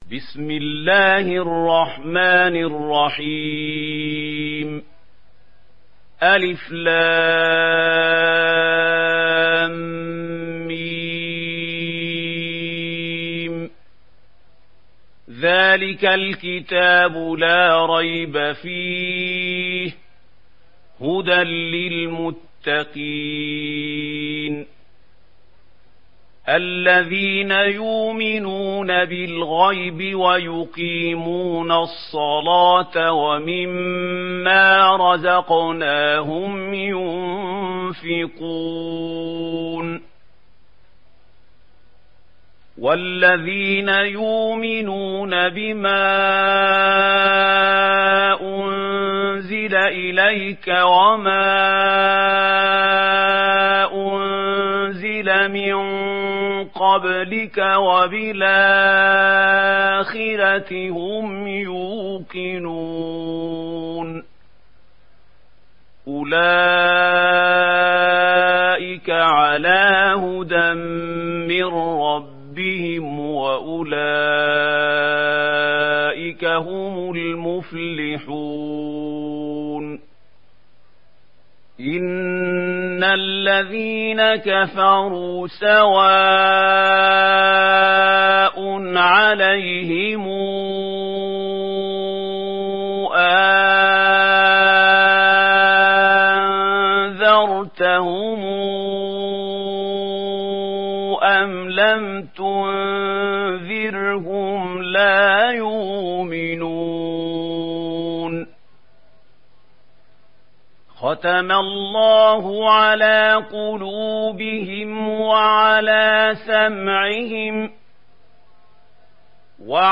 সূরা আল-বাক্বারাহ্ ডাউনলোড mp3 Mahmoud Khalil Al Hussary উপন্যাস Warsh থেকে Nafi, ডাউনলোড করুন এবং কুরআন শুনুন mp3 সম্পূর্ণ সরাসরি লিঙ্ক